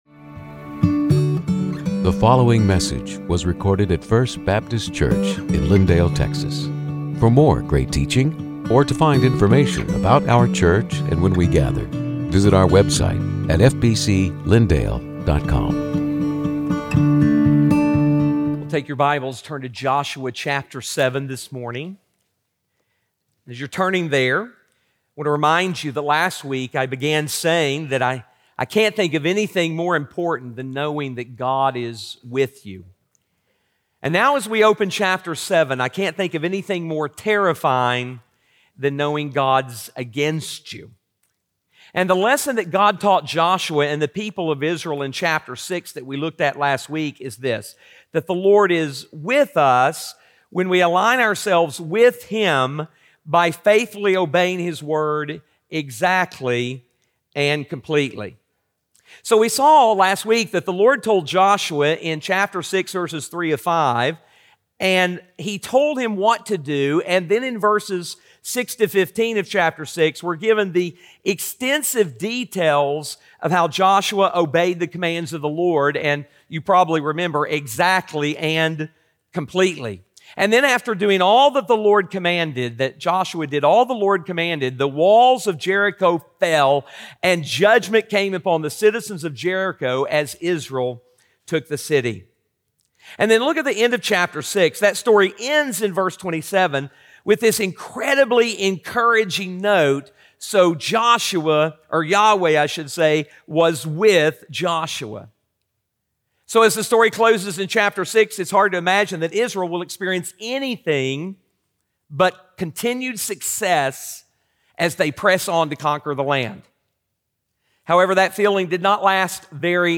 Sermons › Joshua 7:1-26